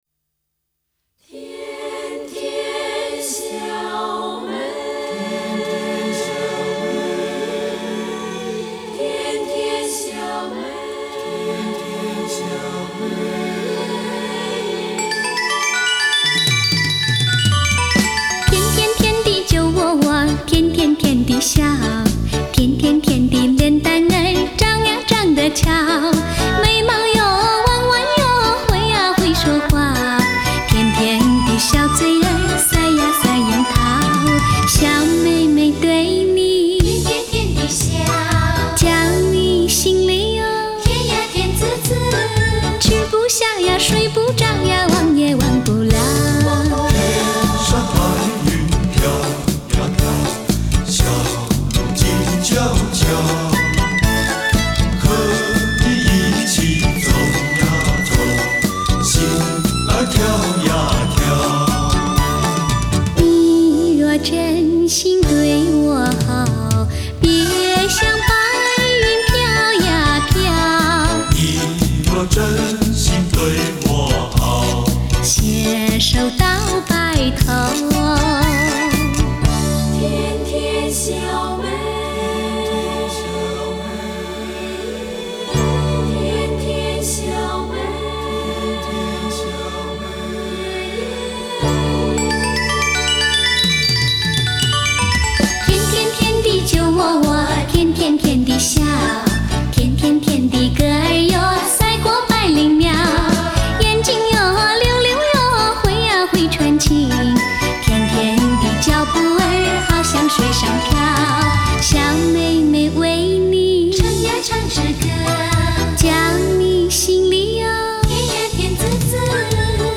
Жанр: Chinese pop